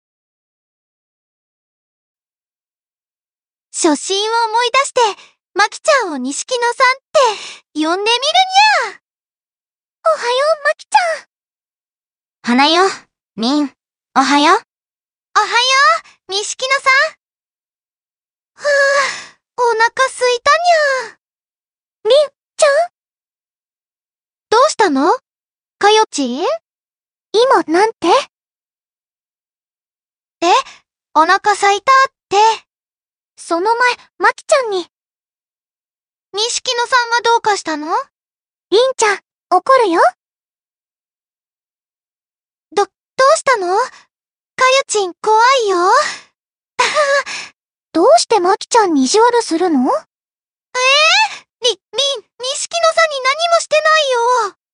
注意：過去のラ！板ｓｓで自分の好きなものを、最近はやりの中華ＡＩツールに読ませてみました。
暗い声色が少ないから悲しんでてもテンション高いな笑
音程の調整はできないんだよ